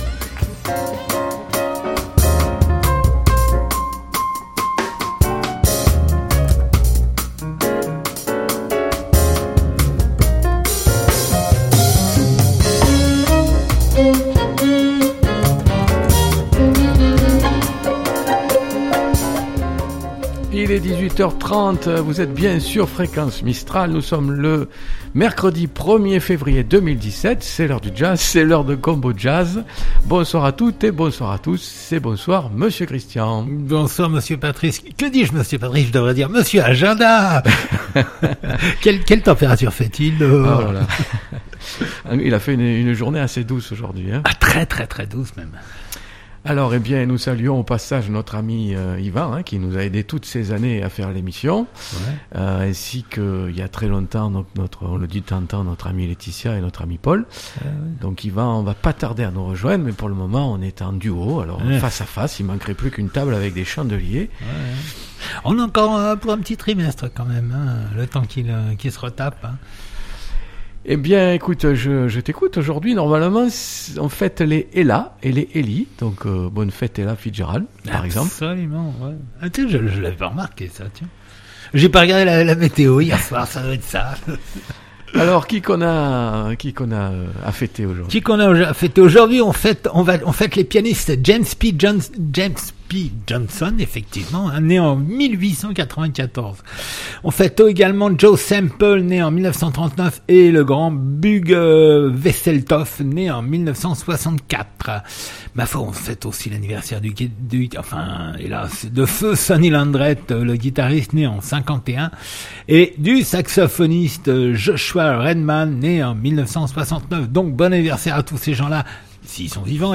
Combojazz du 1er Février 2017 Mercredi 1 Février 2017 Du jazz rien que du jazz 1 mercredi sur 2 de 18h30 à 20h00. écouter : Durée : 1h30'17" Combojazz-2017-02-01.mp3 (63.15 Mo) Fréquence Mistral Manosque